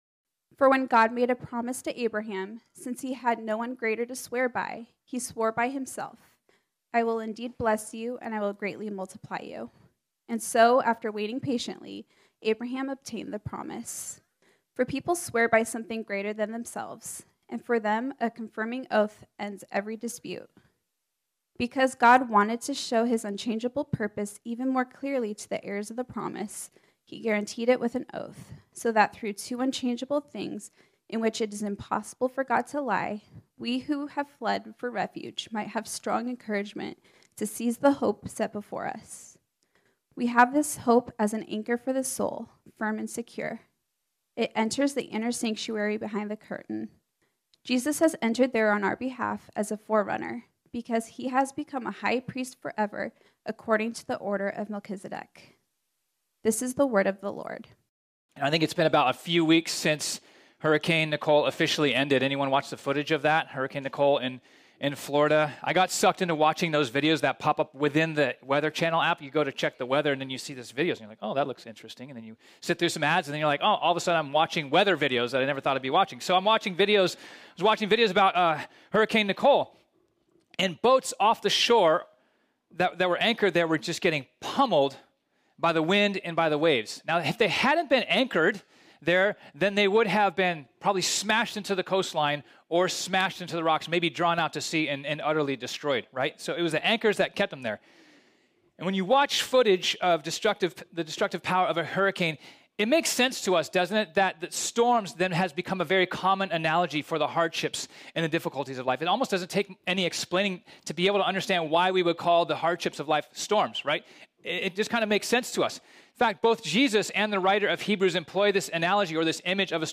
This sermon was originally preached on Sunday, November 27, 2022.